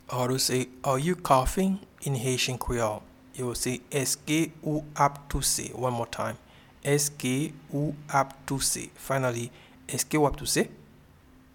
Pronunciation and Transcript:
Are-you-coughing-in-Haitian-Creole-Eske-ou-ap-touse.mp3